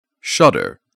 /ˈʃʌdər/